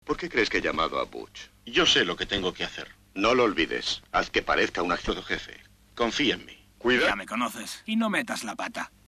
Presentada también en monoaural 1.0, esta pista tiene menos ruido de fondo que otras películas de los Marx, por lo que suena en principio ligeramente mejor.
Sin embargo, la pista castellana es defectuosa, puesto que en varios momentos falta del doblaje normal, que ha sido sustituido durante algunos segundos por unas voces sudamericanas, completamente diferentes y que resultan ridículas por comparación con el resto de la pista sonora.
Como ya hemos dicho, la pista castellana presenta varios momentos en los que el doblaje original ha sido sustituido por unas voces sudamericanas, completamente distintas.